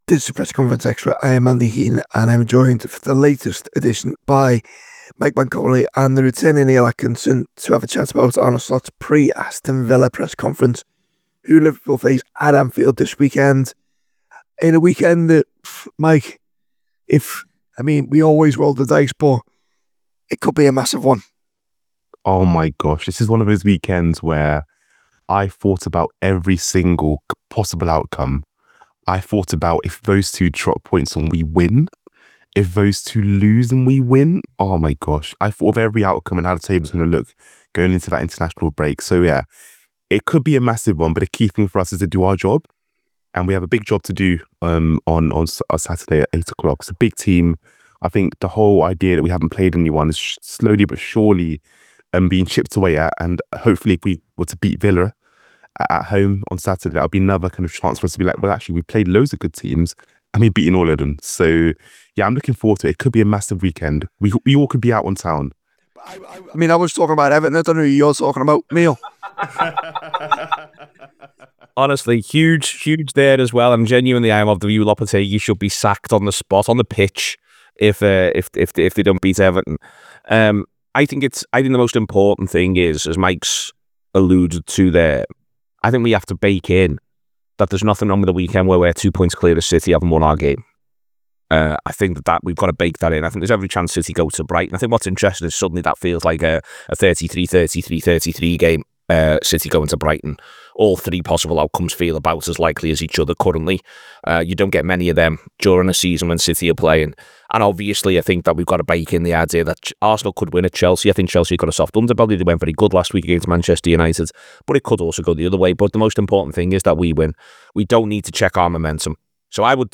Below is a clip from the show – subscribe for more on the Liverpool v Aston Villa press conference…